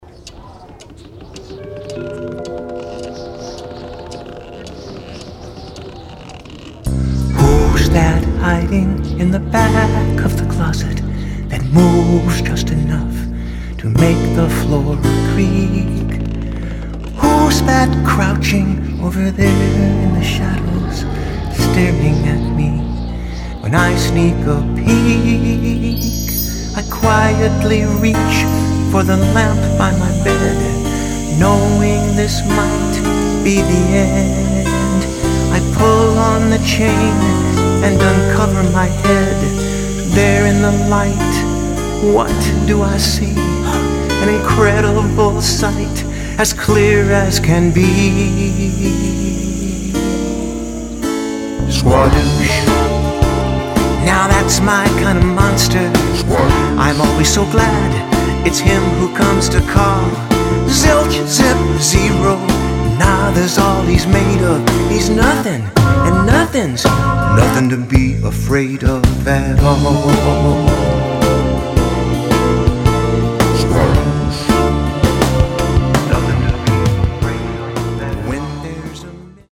Key of Dm
110 bpm
Intro: 4/4 | (Sound Effects) |
Chorus (tempo) (to fade)